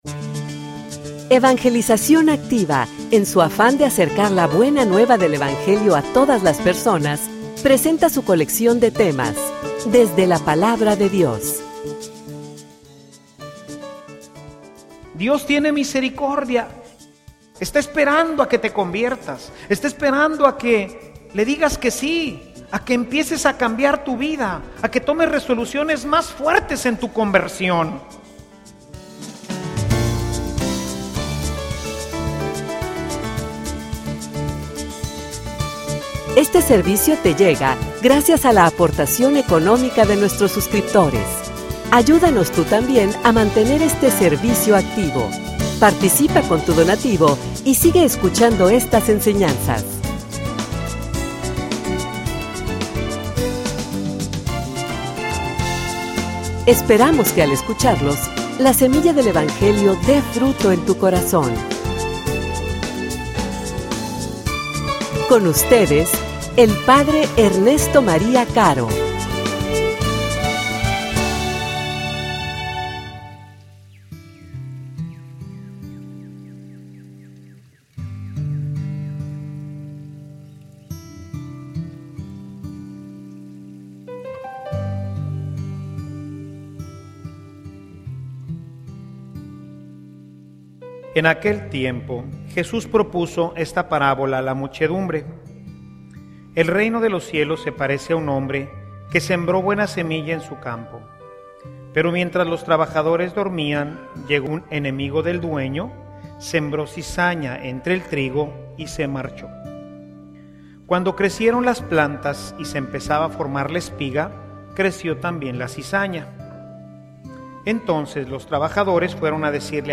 homilia_Aun_hay_tiempo.mp3